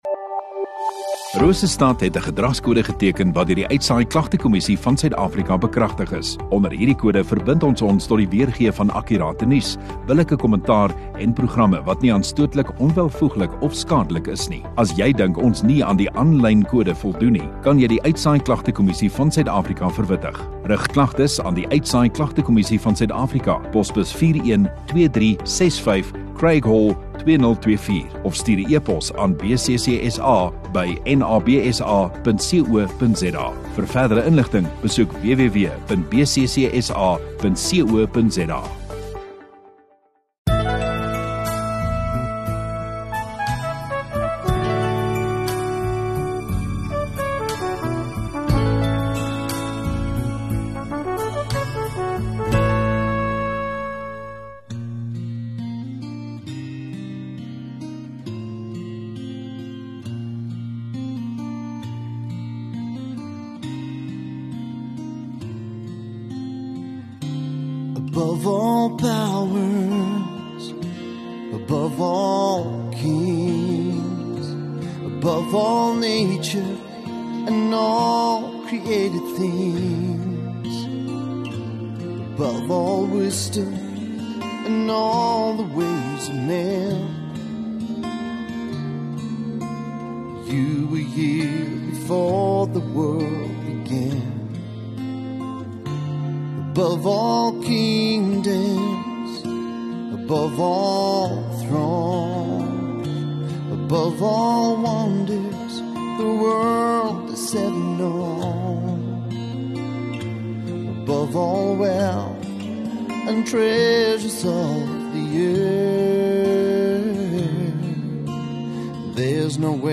7 Dec Sondagoggend Erediens